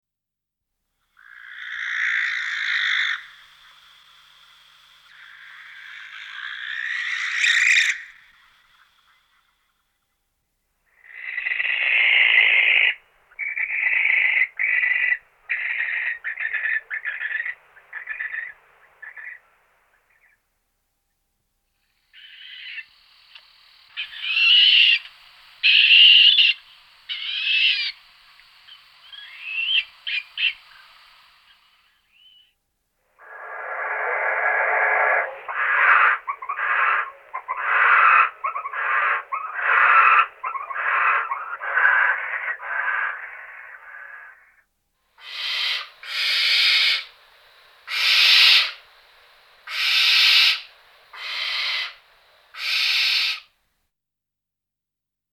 نام فارسی: جغد انبار
نام انگلیسی: Western Barn Owl
نام علمی: Tyto alba
40a.Barn Owl.mp3